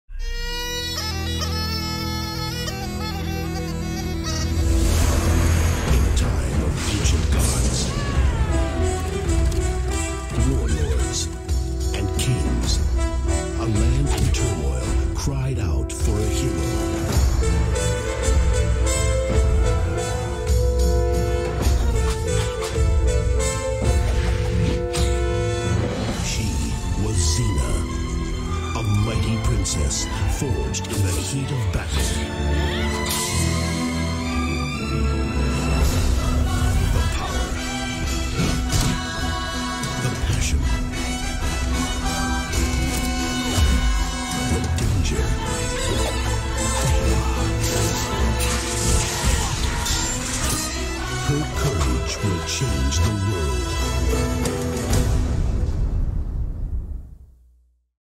Звуковая композиция